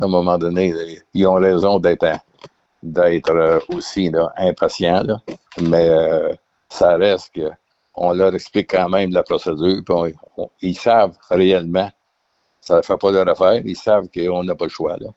Avec ces nombreux travaux sur les routes, le maire, André Descôteaux, comprend la frustration des automobilistes